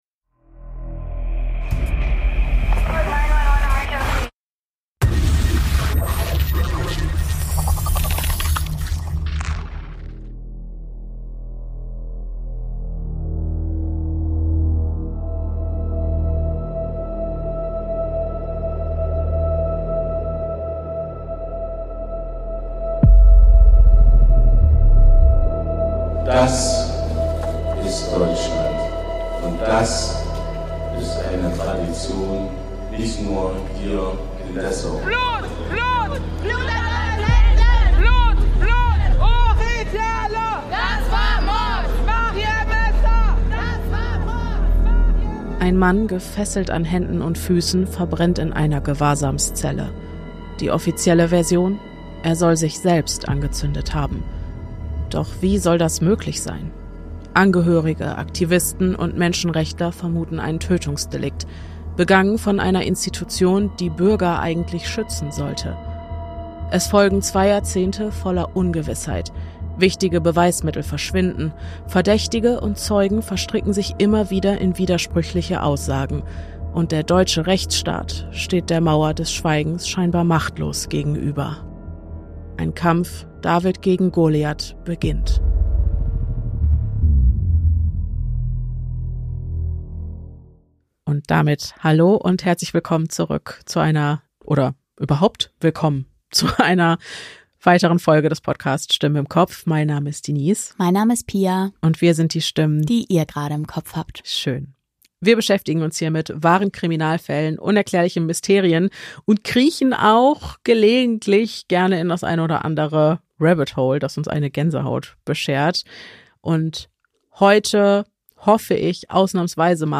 Heute wird sich wieder gegruselt! Eure Geschichten von uns für euch gelesen.